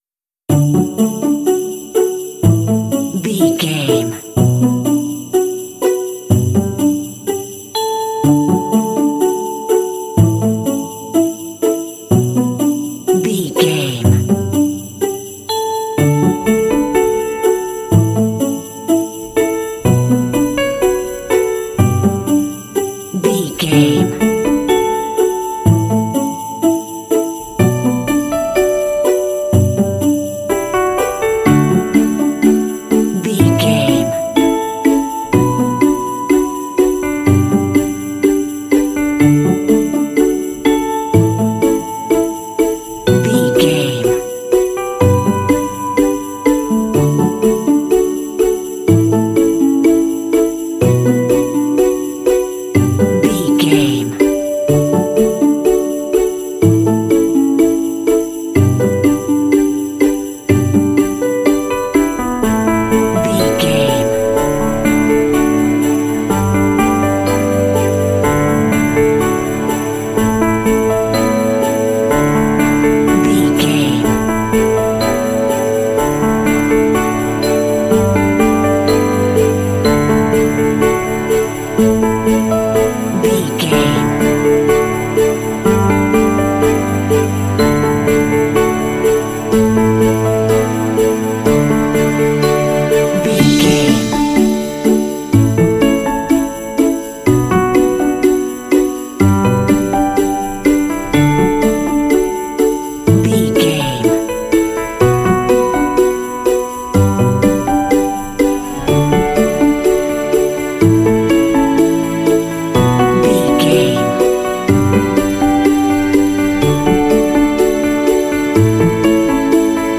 Ionian/Major
D
happy
festive
uplifting
strings
piano